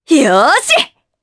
Naila-Vox_Happy4_jp.wav